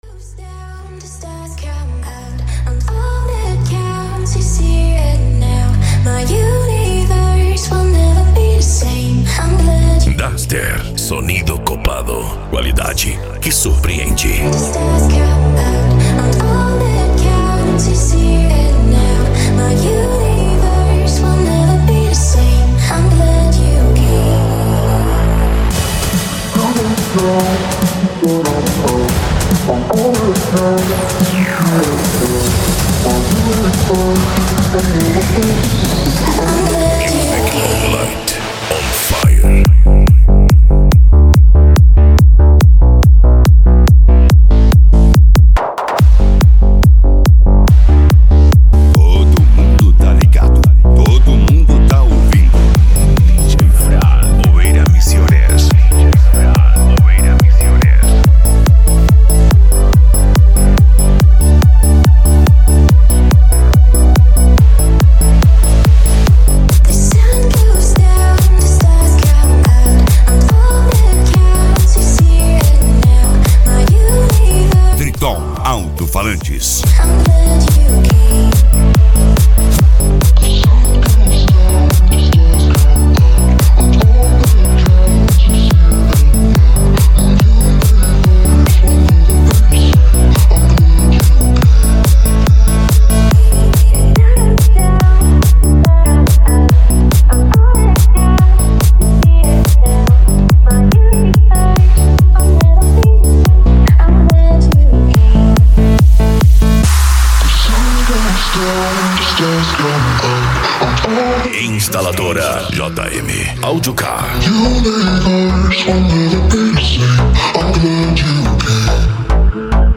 Eletronica
Psy Trance
Remix